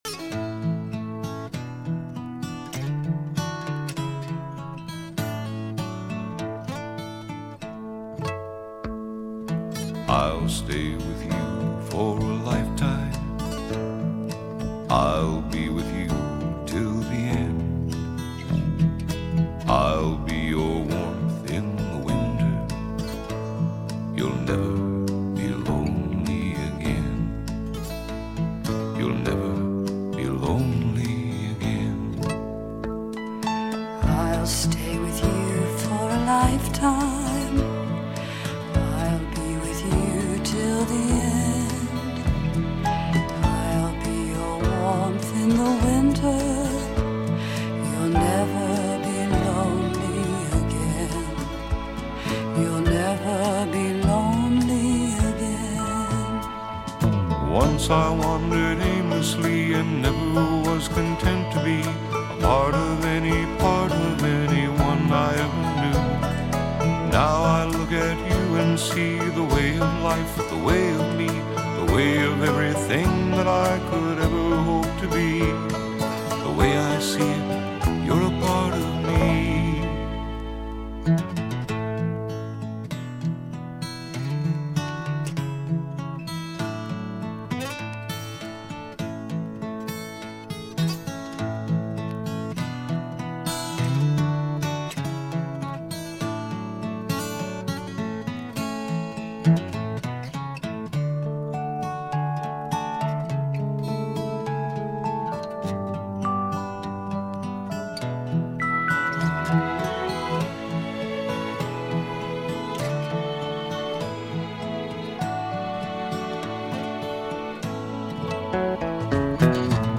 This folk music
drums
piano
bass guitar
harmonica
banjo
strings
vocals
Folk music--Iowa